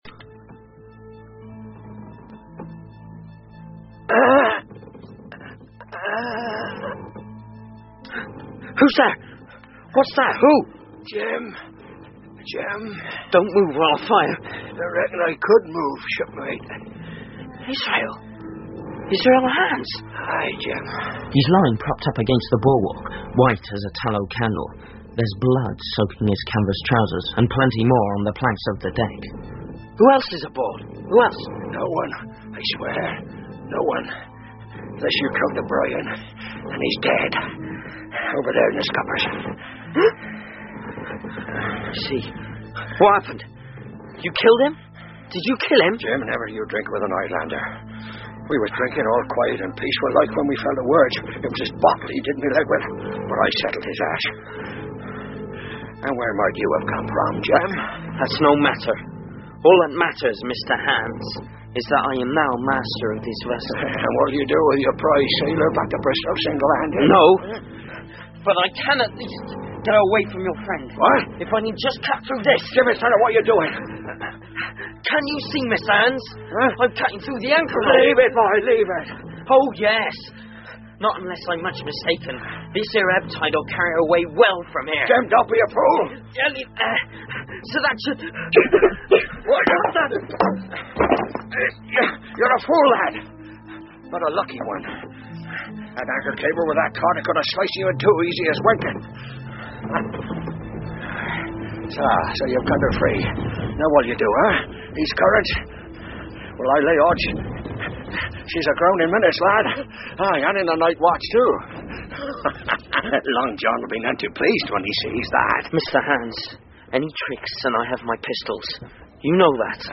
金银岛 TREASURE ISLAND 儿童英文广播剧 13 听力文件下载—在线英语听力室